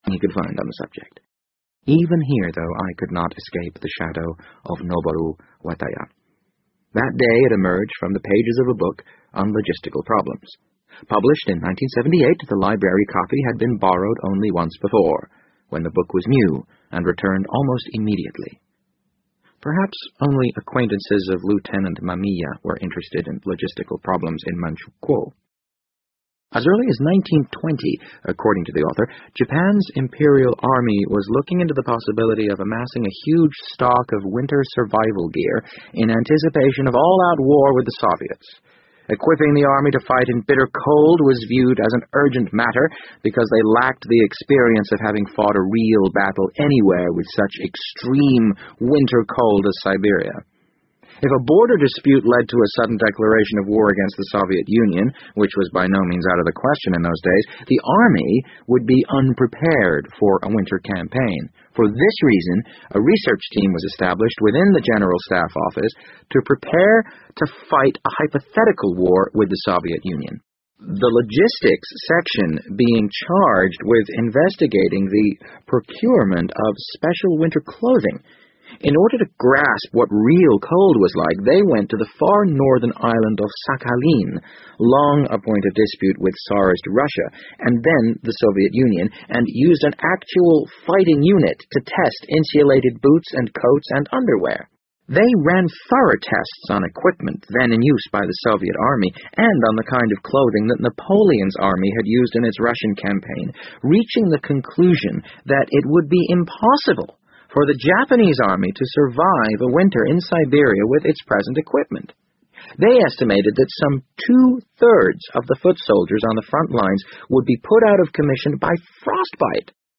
BBC英文广播剧在线听 The Wind Up Bird 012 - 19 听力文件下载—在线英语听力室